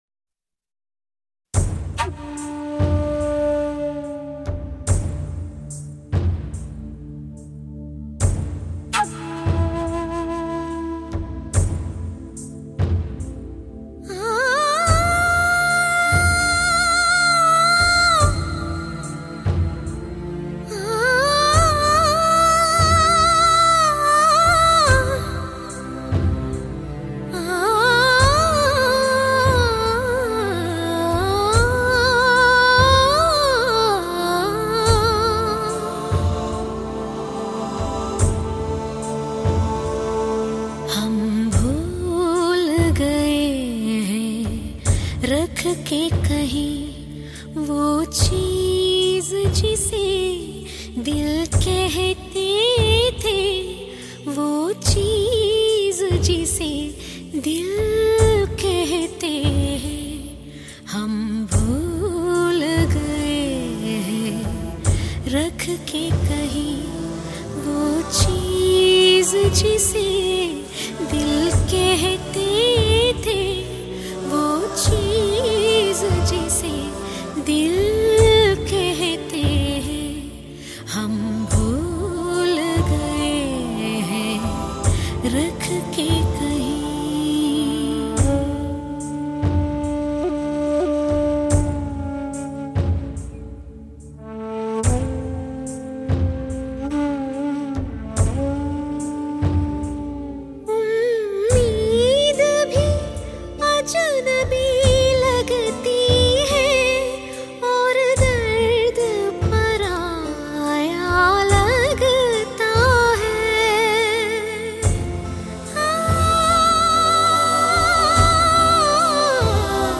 Hindi & Bollywood